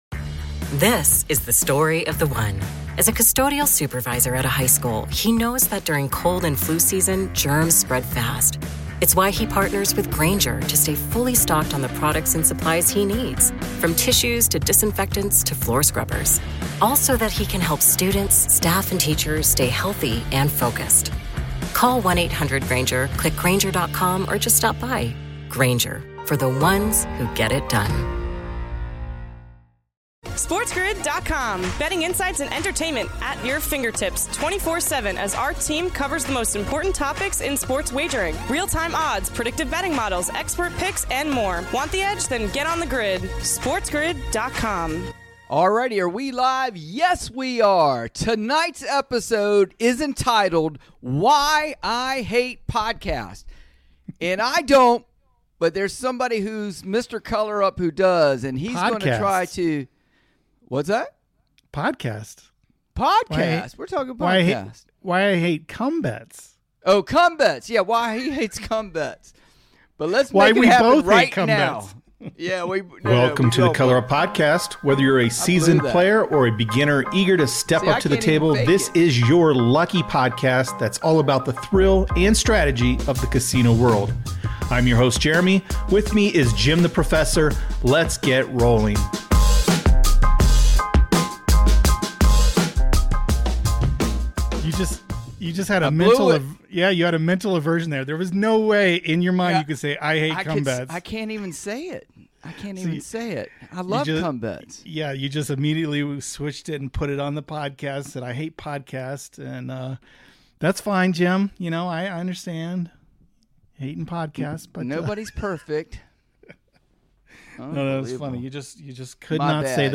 engage in a humorous and light-hearted discussion about their differing opinions on come bets in craps